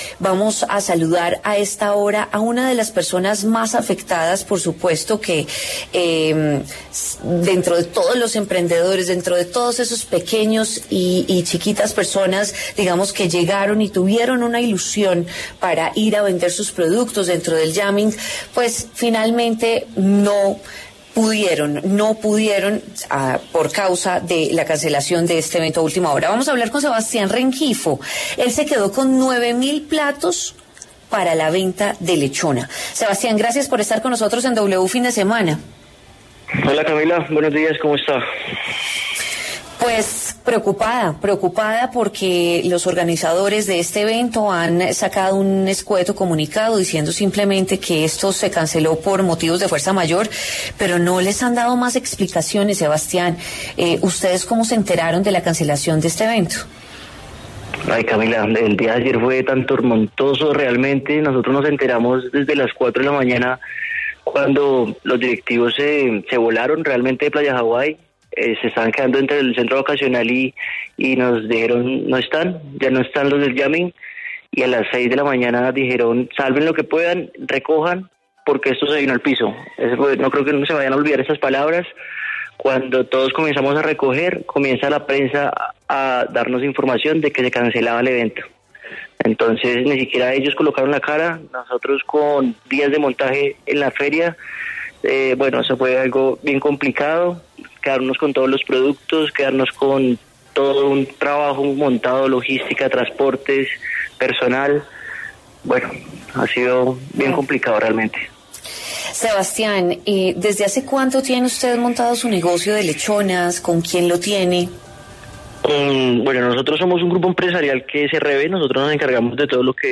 En entrevista con W Fin de Semana